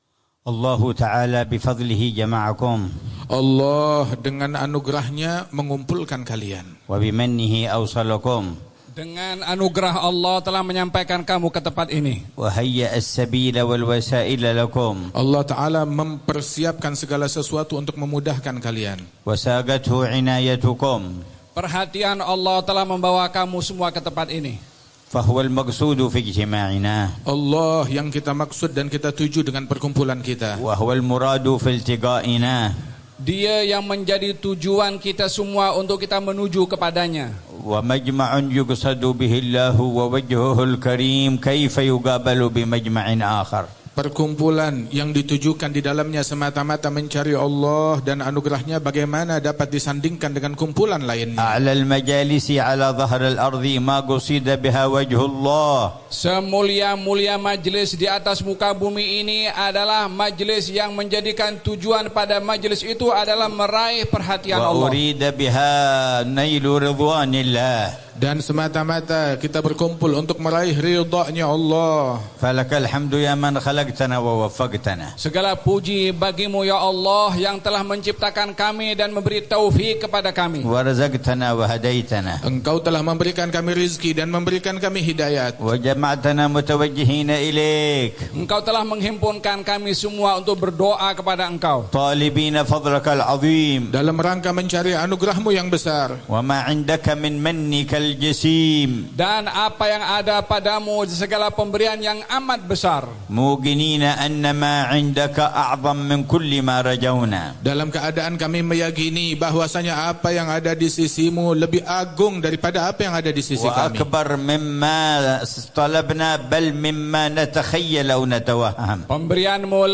محاضرة في المجلس الكبير في فطاني، تايلاند 1447هـ Tabligh Akbar - Pattani, Thailand
محاضرة العلامة الحبيب عمر بن حفيظ في مجلس الوعظ والتذكير الكبير في استاد فطاني، في تايلاند، ليلة الخميس 1 جمادى الأولى 1447هـ